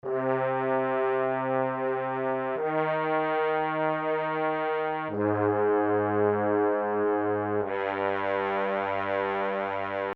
喇叭 混乱
描述：应要求提供更多管弦乐的东西。
Tag: 190 bpm Orchestral Loops Brass Loops 1.70 MB wav Key : Unknown